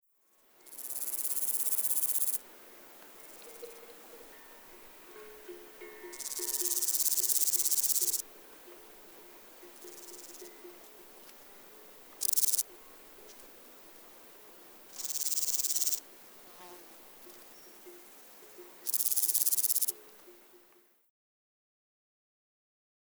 Guia sonora dels Insectes de Catalunya: grills, saltamartins i cigales.
42_demo3_43_Stenobothrus_Stigmaticus.mp3